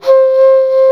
59 FLUTE 2-L.wav